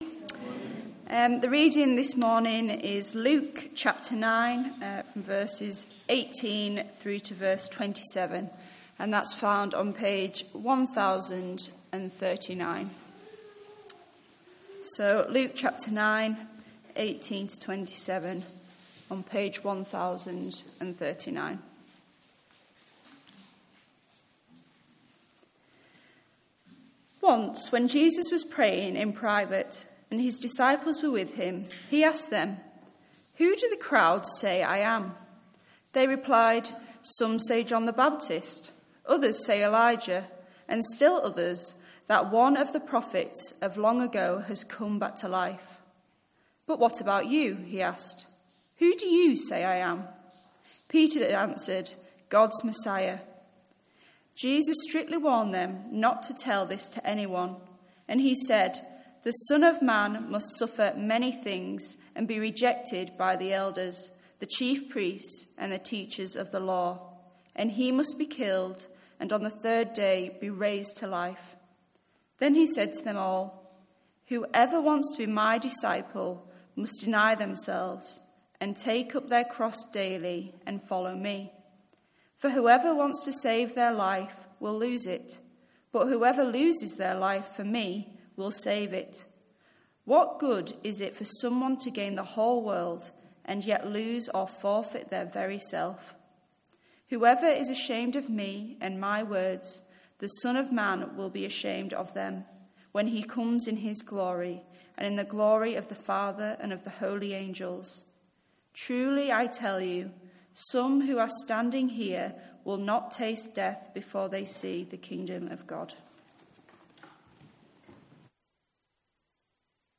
Service Type: Morning Service 11:15